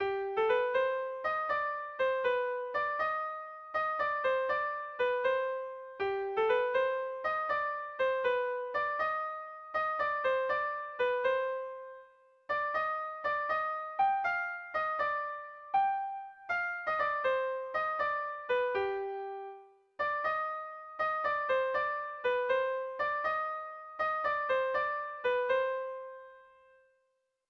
Bertso melodies - View details   To know more about this section
Kontakizunezkoa
ABDEF